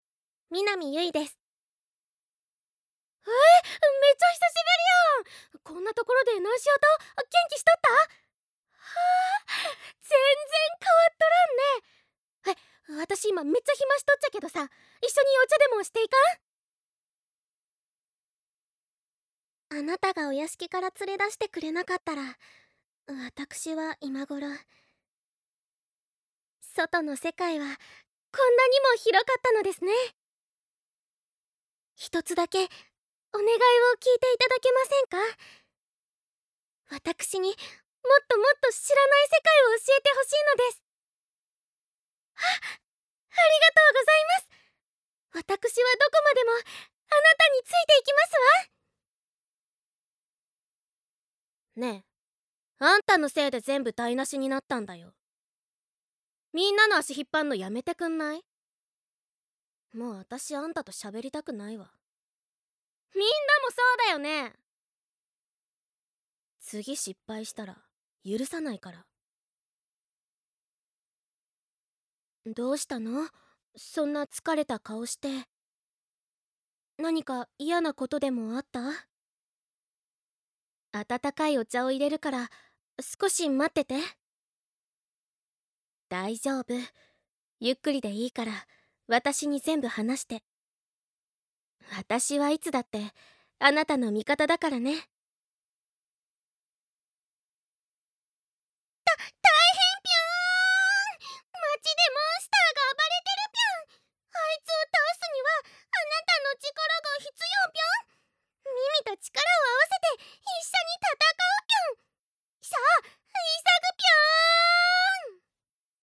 サンプルボイス
方言 北九州弁